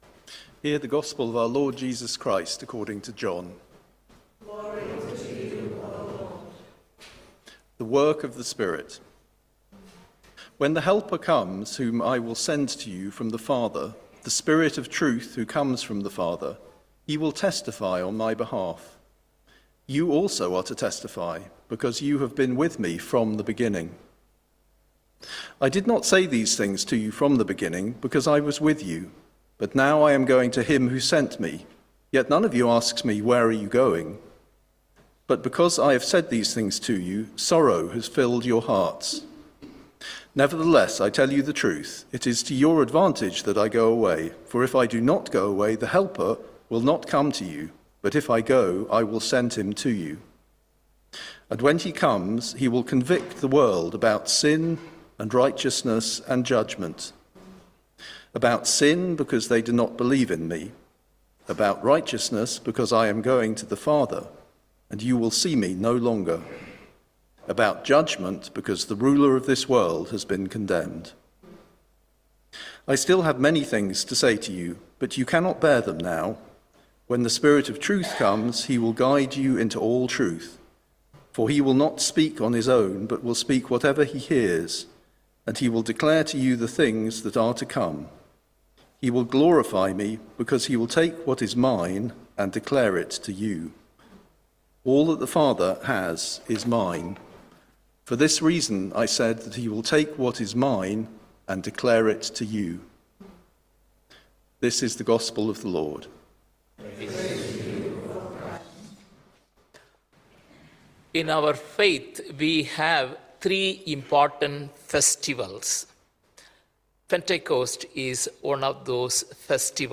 Sermon-19th May, 2024